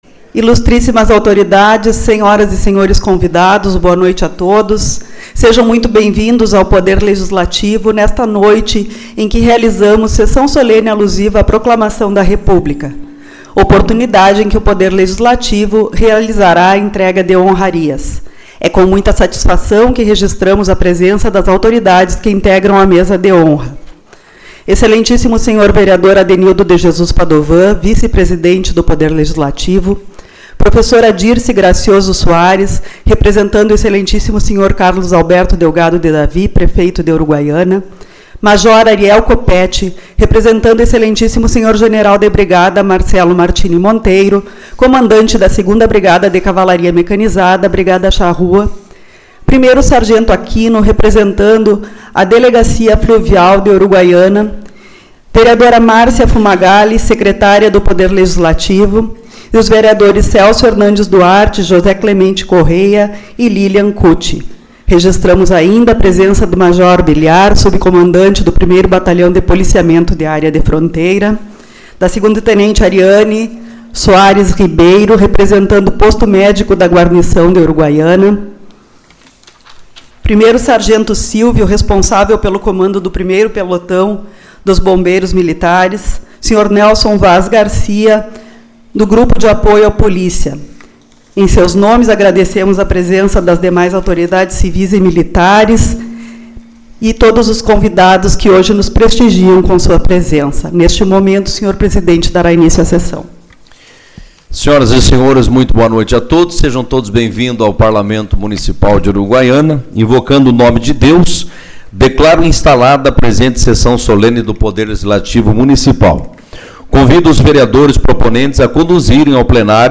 13/11 - Sessão Solene-Proclamação da República